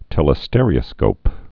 (tĕlĭ-stĕrē-ə-skōp, -stîr-)